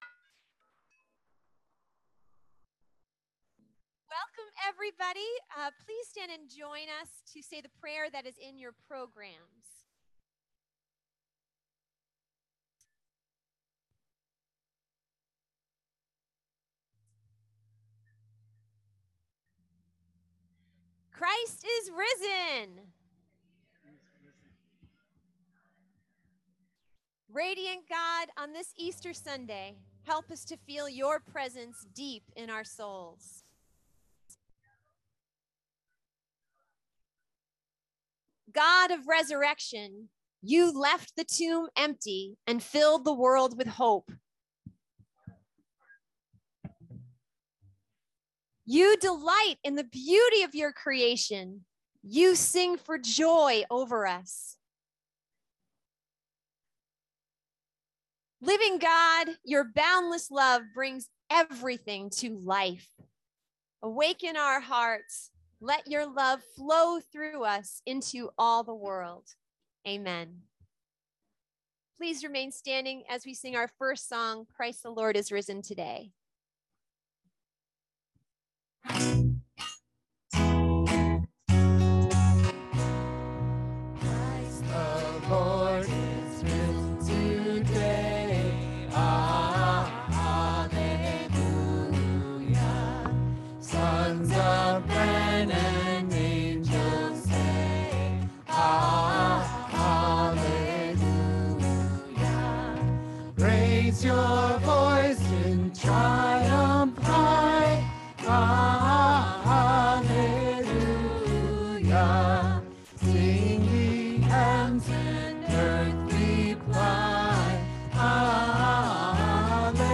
Message
outdoor Easter Sunday Service